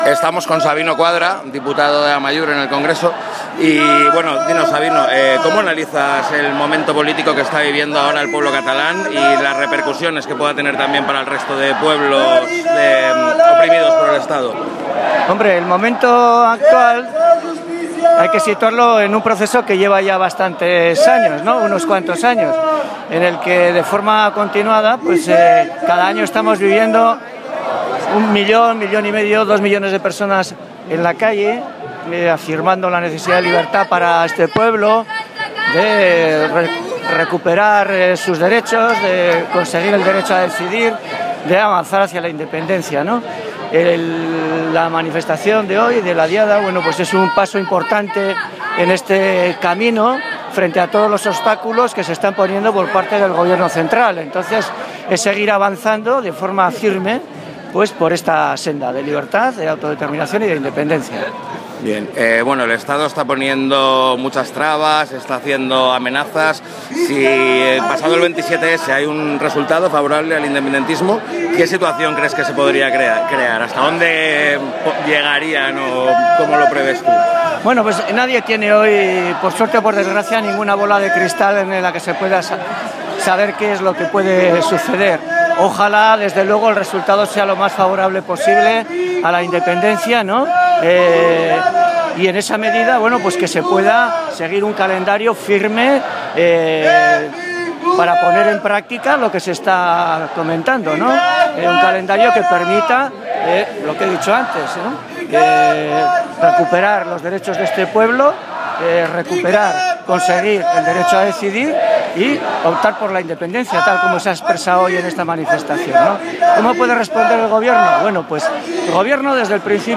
La Haine entrevista a Sabino Cuadra, de Amaiur: